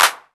Clap Funk 1.wav